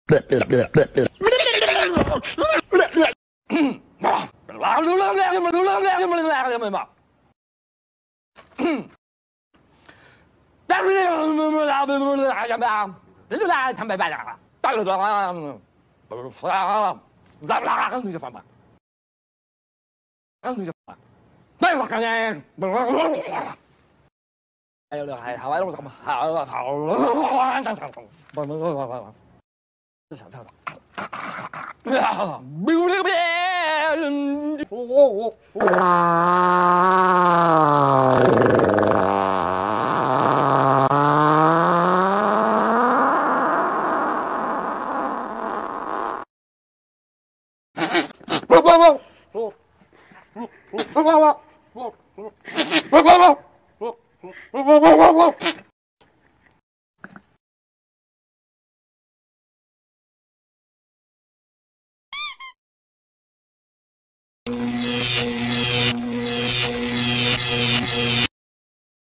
Its the cough that gets me every time.